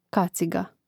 kàciga kaciga